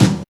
1810L BD.wav